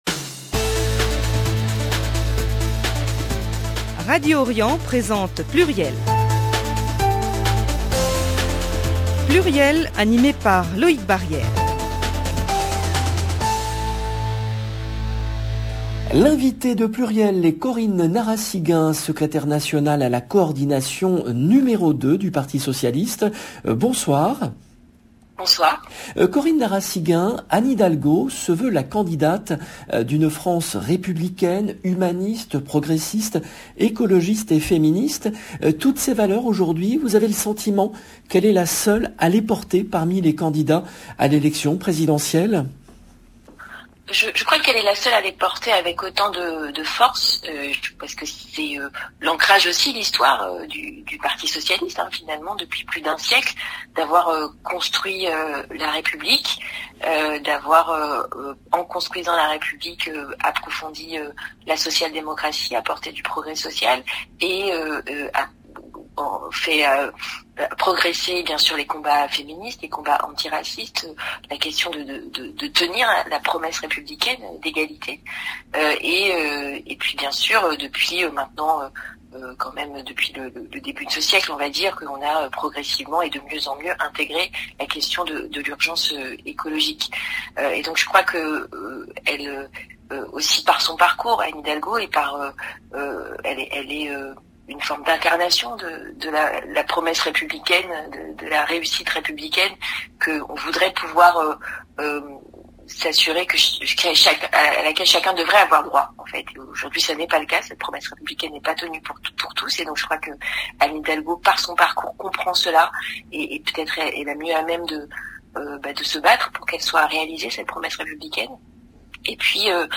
L’invitée de PLURIEL à 19h et 23h est Corinne Narassiguin , Secrétaire nationale à la Coordination, n°2 du Parti Socialiste